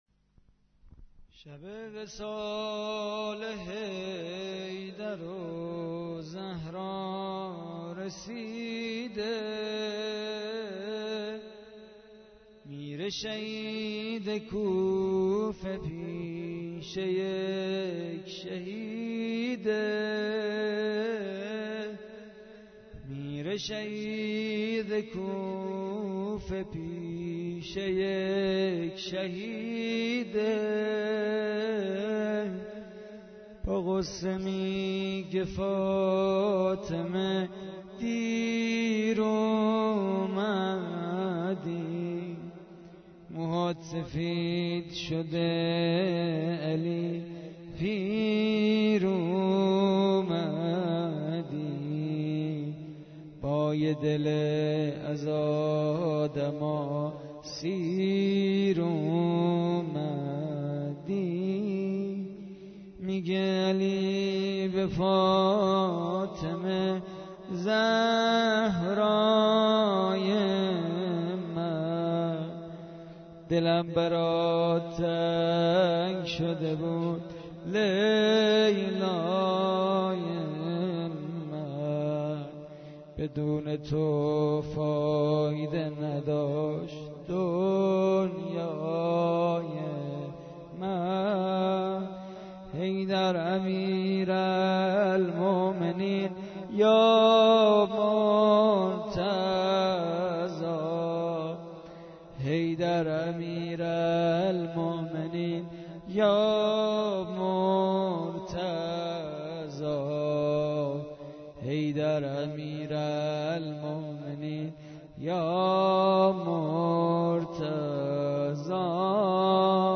متن مداحی شب بیست و یکم ماه رمضان به سبک زمزمه -( شب وصال حیدر و زهرا رسیده ، میره شهید کوفه پیش یک شهیده )